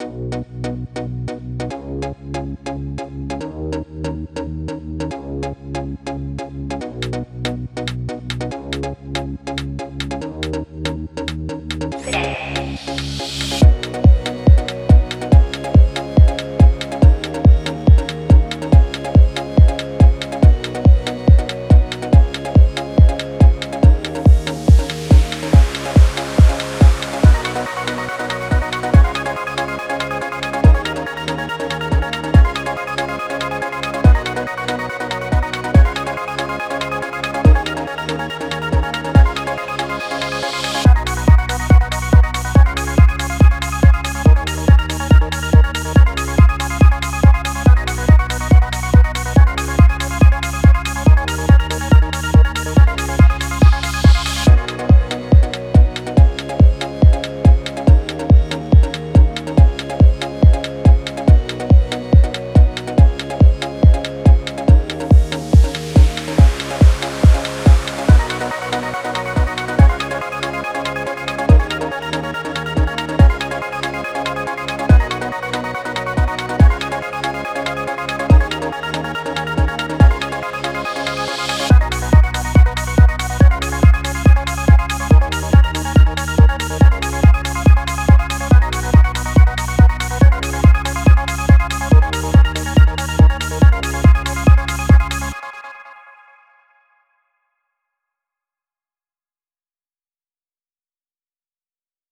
Time – (1:42)　bpm.141